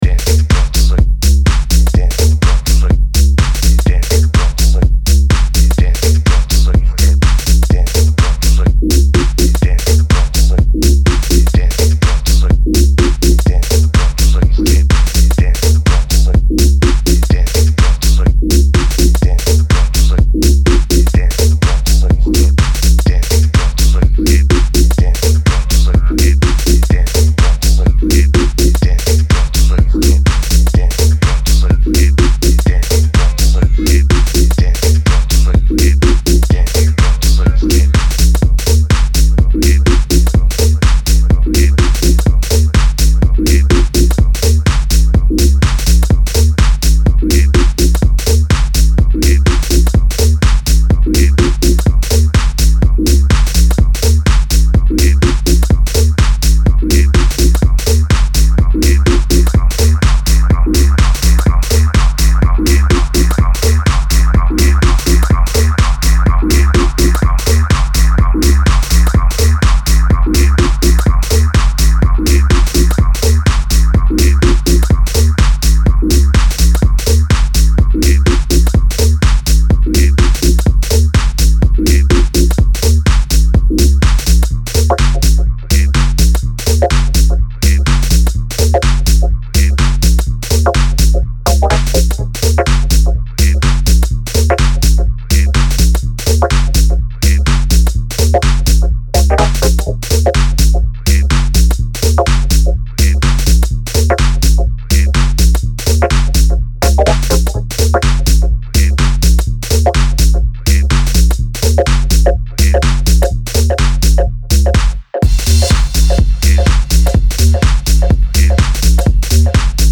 and a sampler of swing, groove